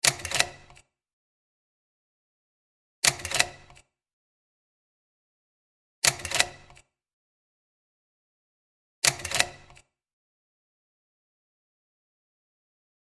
Механический звук переключения слайда в диафильме (4 щелчка)